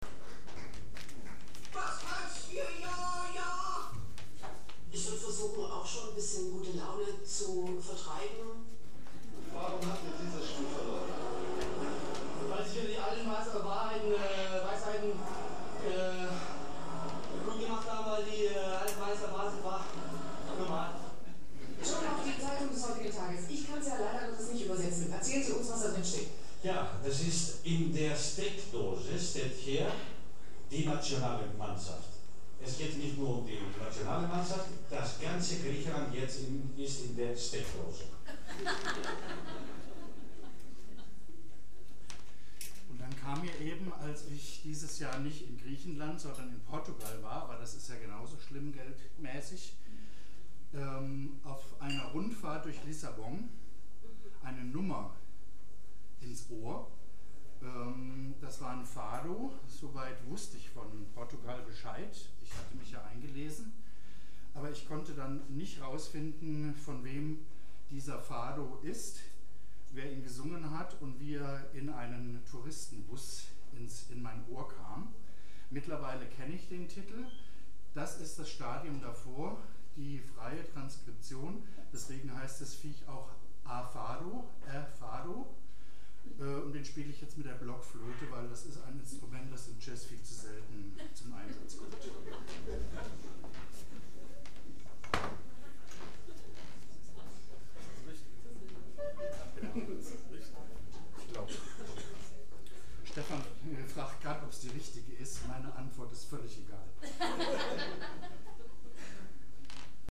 4 03 Ansage [1:50]
03 - Ansage.mp3